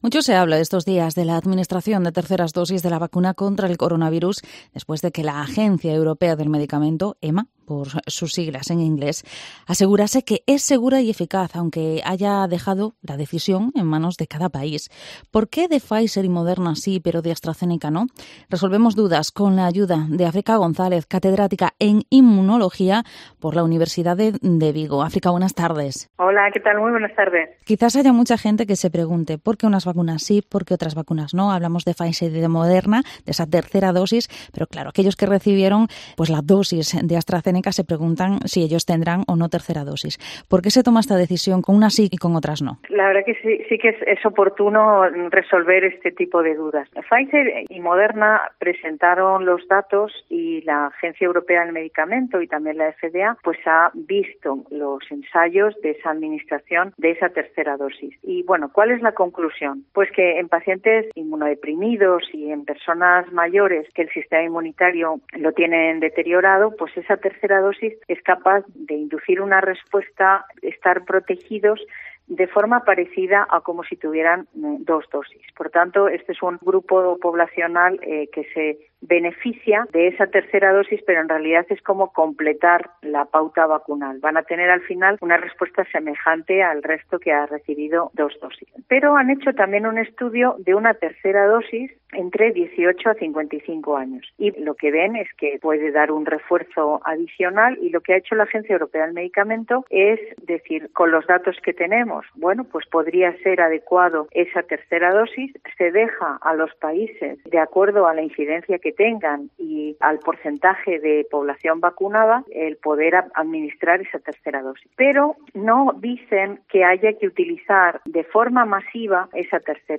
Vigo Entrevista ¿Por qué segunda dosis de Pfizer y Moderna y no de Astrazeneca?